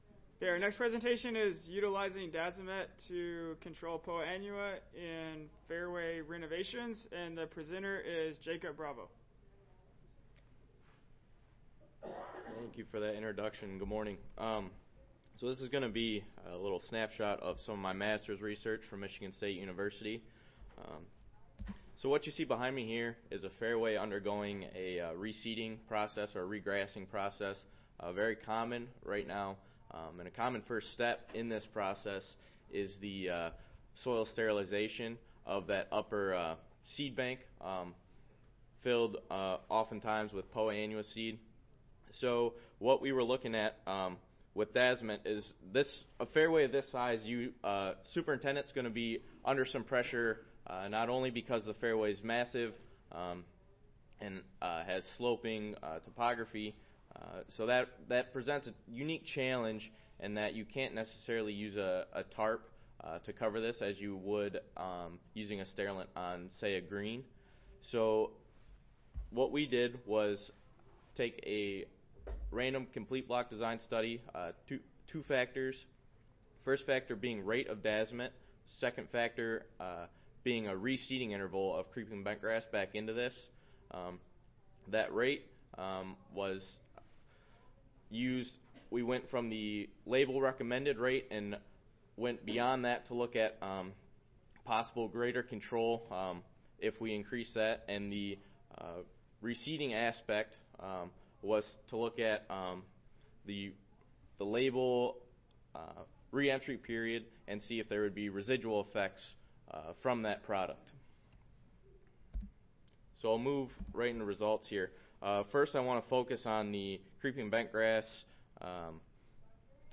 Oral Session
Audio File Recorded Presentation